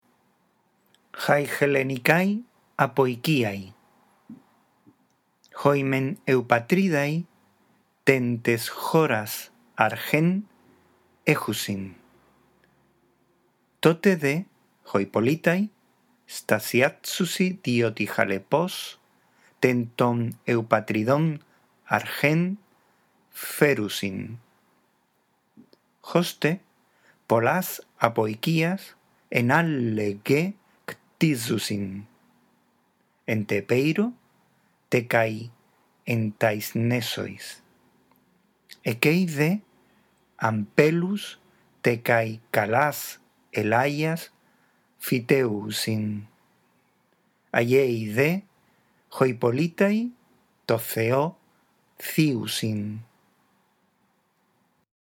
La audición de este archivo contribuirá a la práctica de la lectura del griego